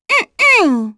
Hanus-Vox-Deny1.wav